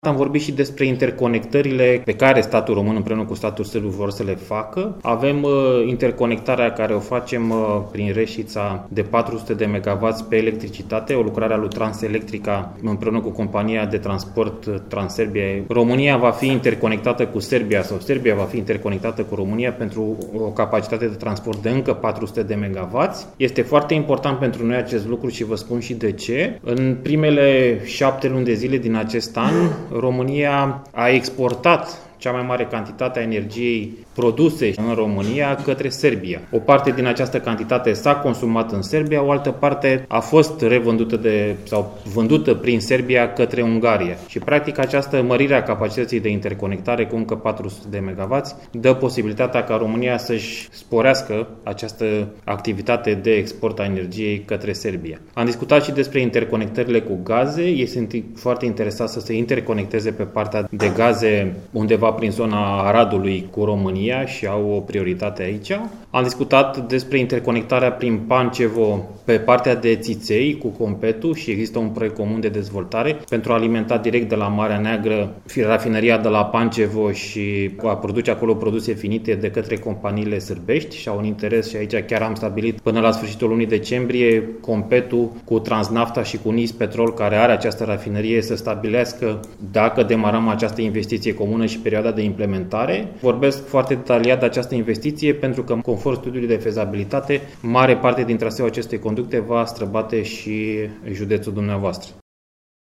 Anunțul a fost făcut la Reșița de ministrul de resort, Toma Petcu.